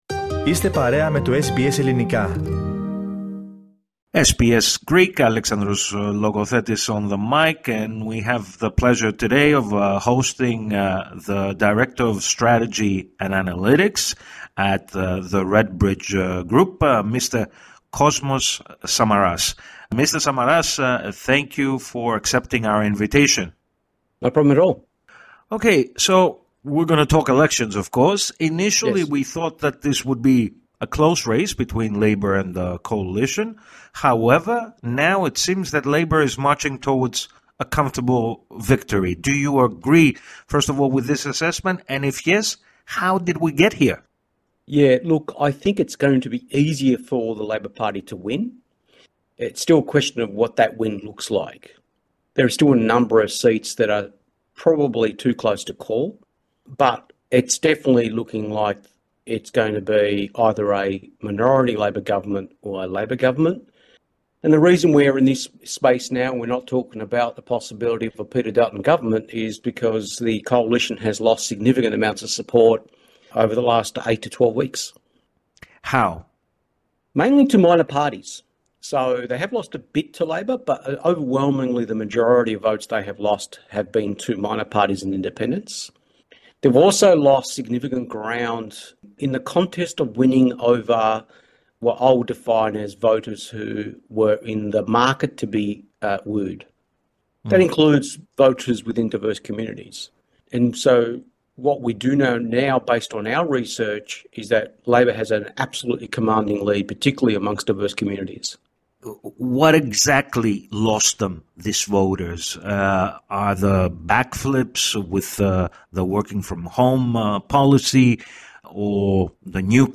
Λίγες μέρες έχουν απομείνει μέχρι και την επίσημη διεξαγωγή των ομοσπονδιακών εκλογών, το Σάββατο 3 Μαΐου. Με αυτή την αφορμή, μίλησε στο Ελληνκό Πρόγραμμα της ραδιοφωνίας SBS